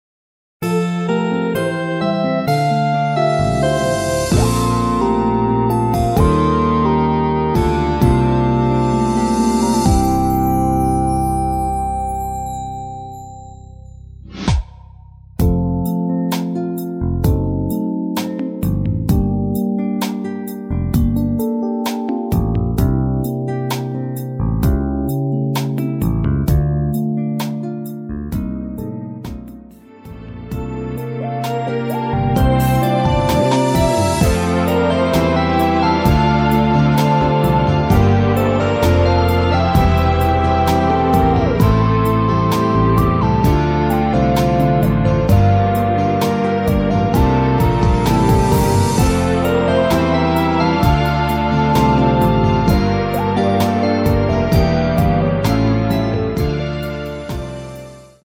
원곡의 보컬 목소리를 MR에 약하게 넣어서 제작한 MR이며